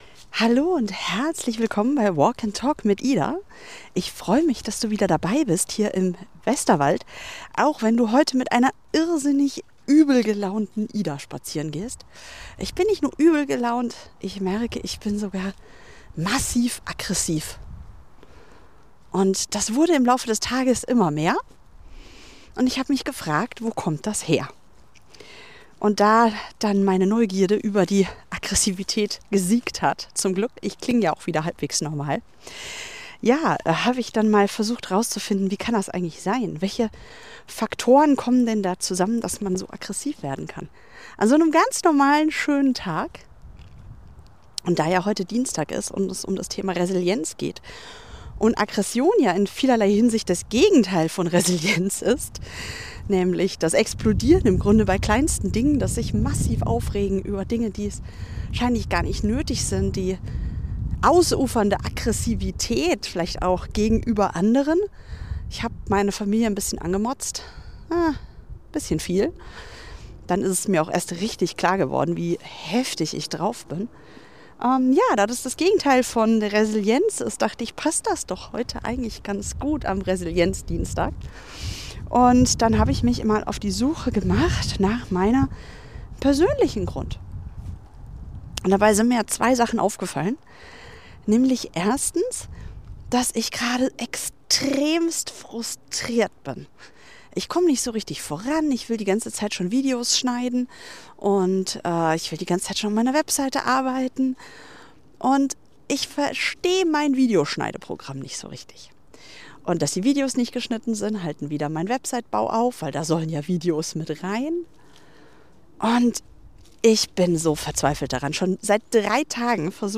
In dieser spontanen Episode nehme ich dich mit auf einen ehrlichen und persönlichen Spaziergang durch meine eigene Aggressivität.